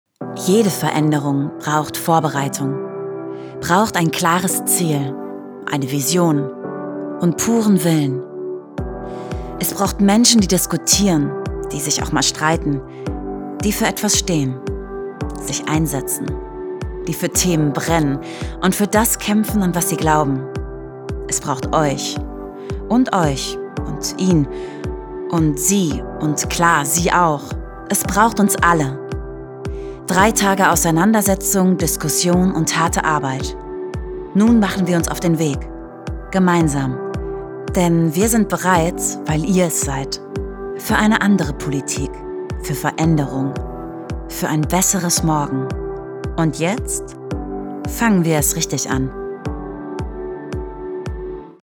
Sprecherin
Sprechproben